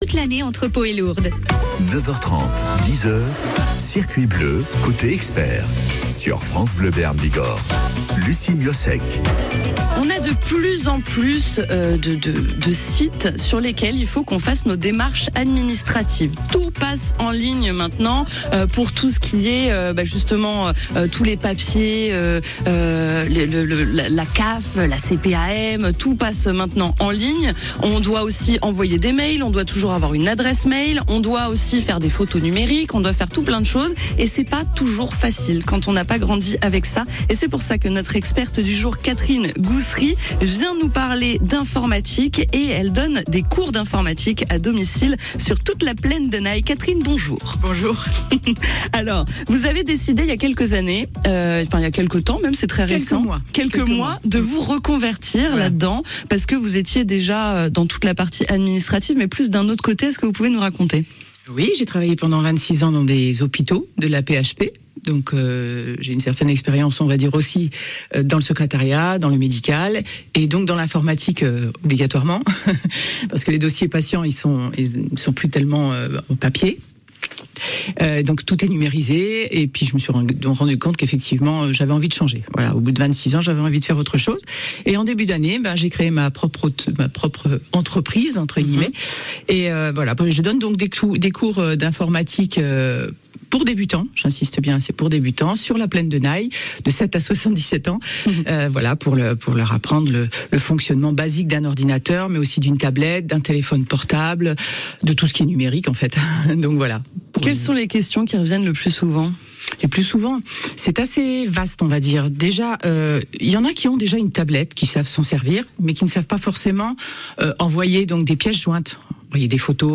Mon interview sur France Bleu Béarn